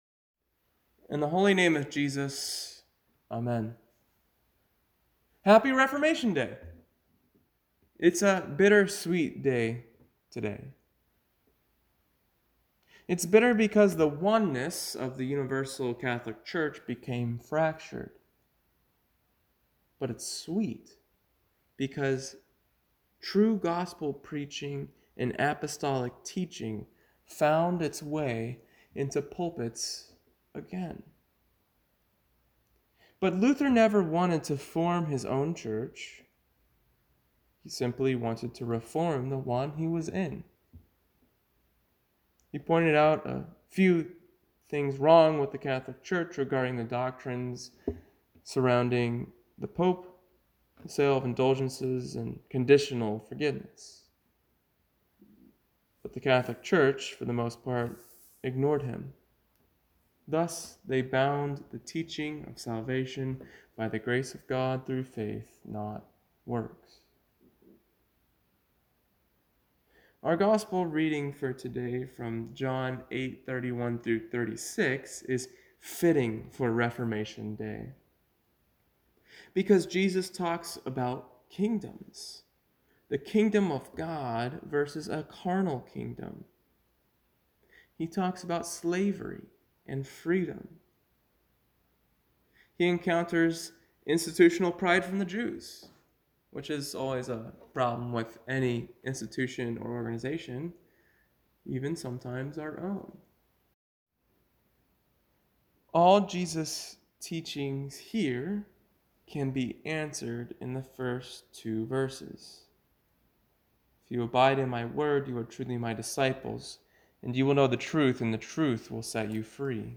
Recent Sermons
Pre-recorded on Friday, October 25 before Sunday, October 27 Reformation Day (Observed)